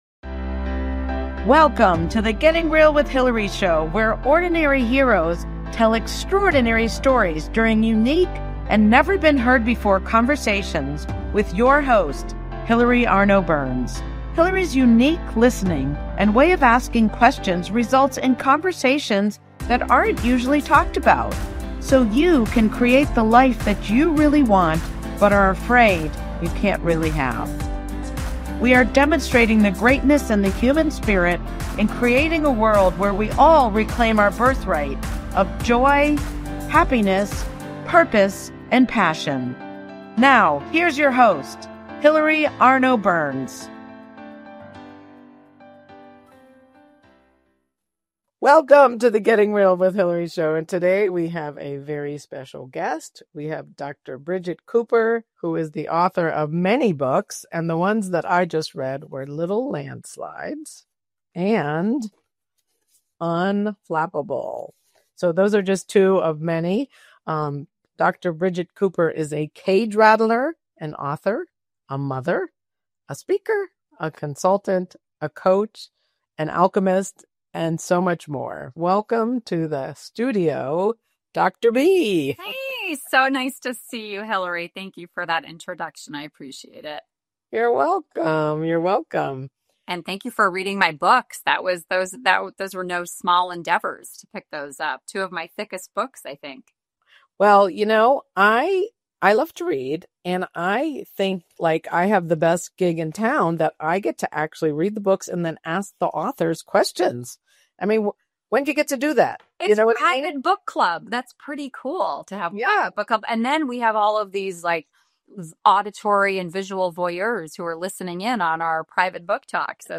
Guests share their powerful stories of transformation and empower our audience to do the same.